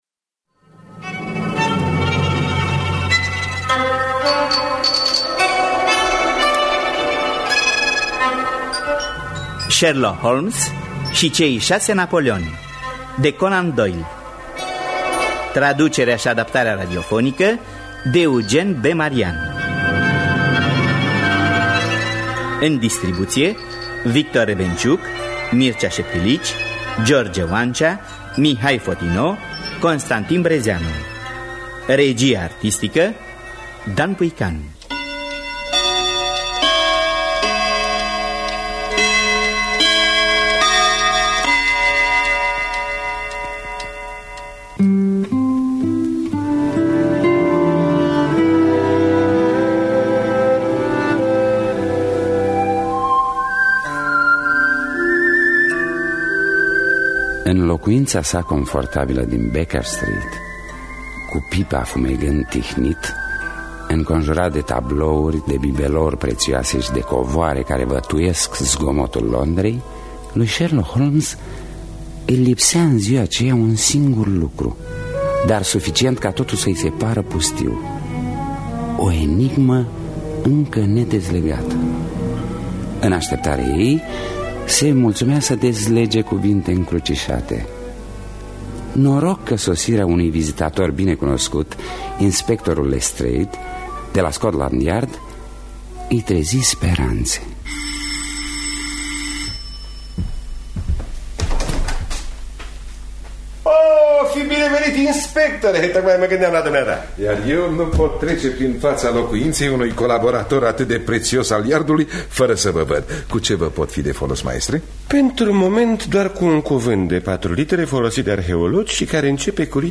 Sherlock Holmes şi cei şase napoleoni de Arthur Conan Doyle – Teatru Radiofonic Online